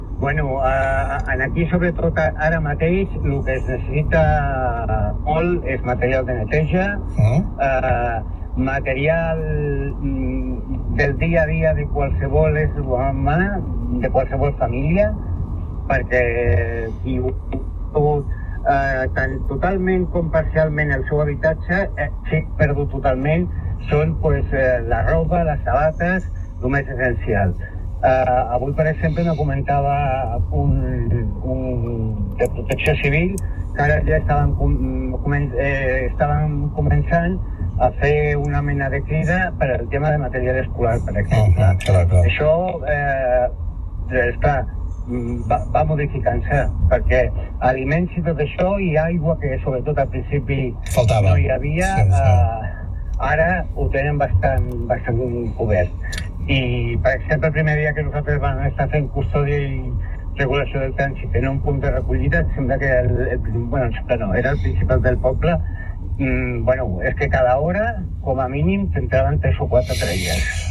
En una entrevista a Tarda Capital